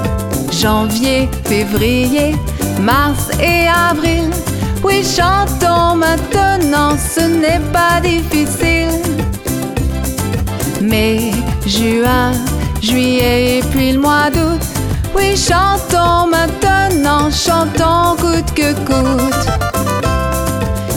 upbeat song
This French song